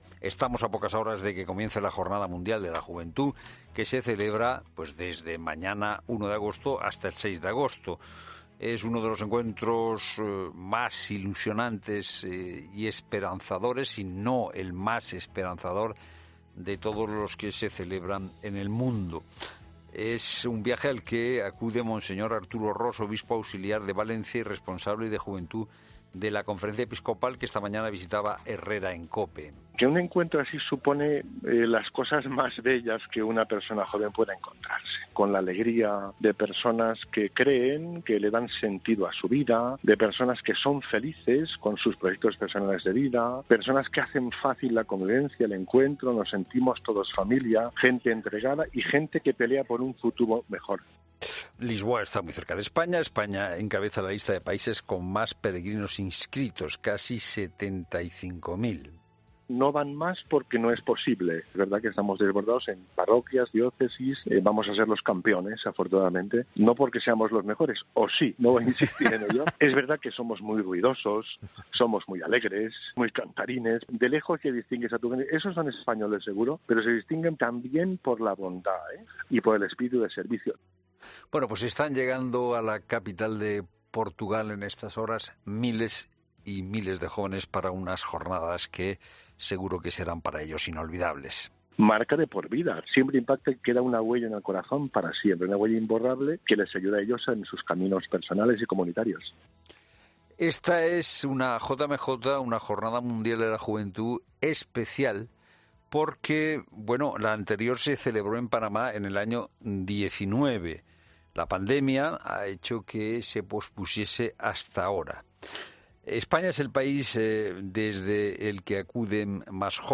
'La Tarde' entrevista a jóvenes en la Jornada Mundial de la Juventud: "Te lanza a querer vivir"